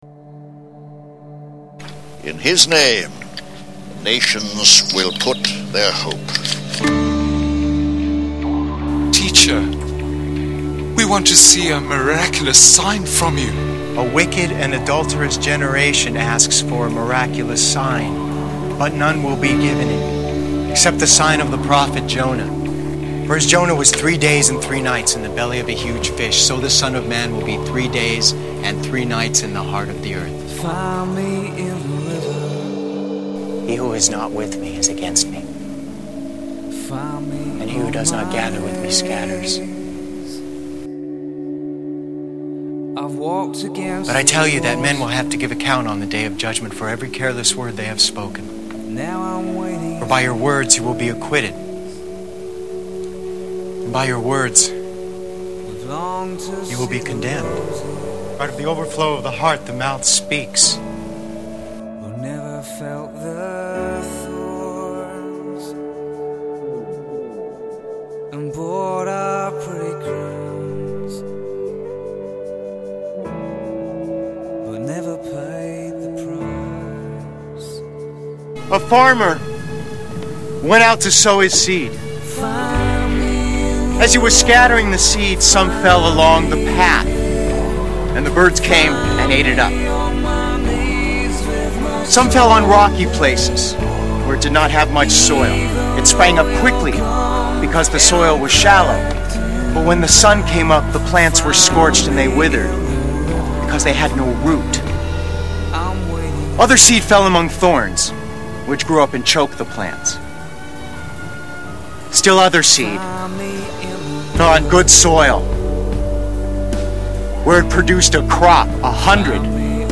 The sermon emphasizes the hope found in Jesus through understanding His teachings and recognizing His compassion and provision for humanity.